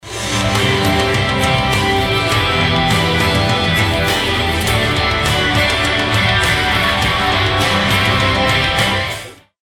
hidden guitar part